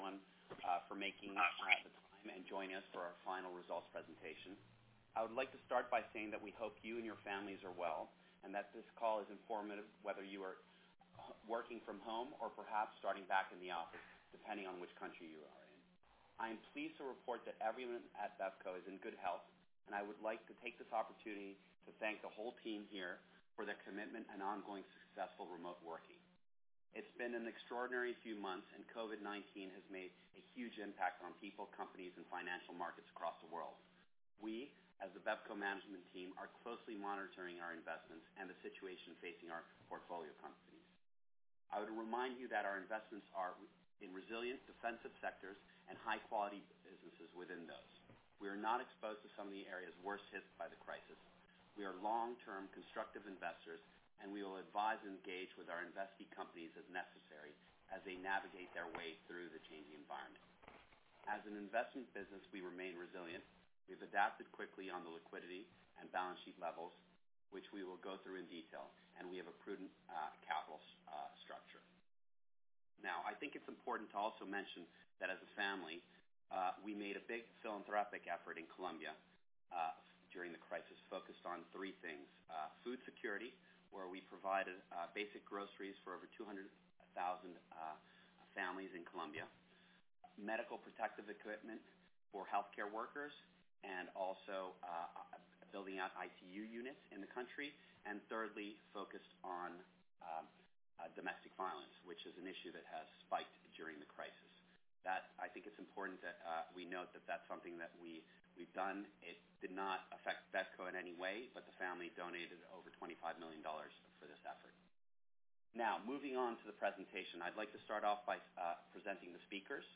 Full year results 2019 – Presentation recording